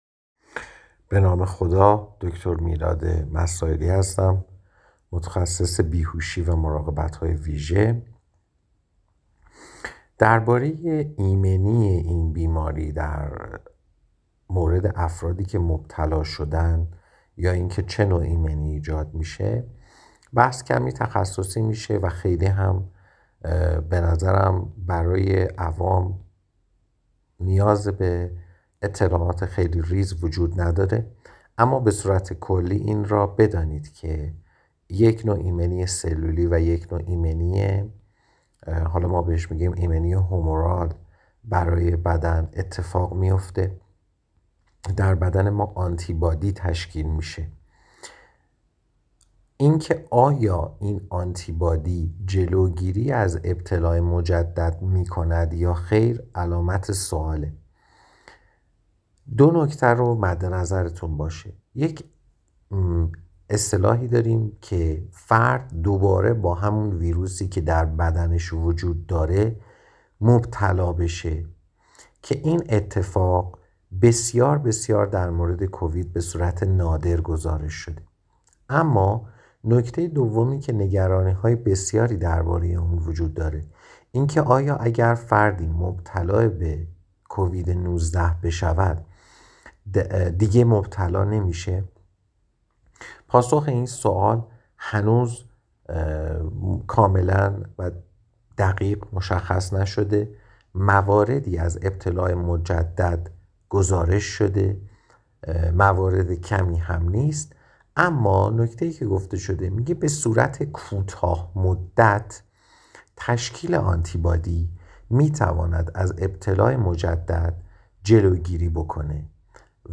در گفت‌وگو با ایسنا